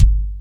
SW KCK2.wav